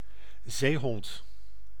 Ääntäminen
Synonyymit rob Ääntäminen Tuntematon aksentti: IPA: /ˈzeɪ̯ɦɔnt/ IPA: /ˈzeːɦɔnt/ Haettu sana löytyi näillä lähdekielillä: hollanti Käännös 1. phoca {f} Suku: m .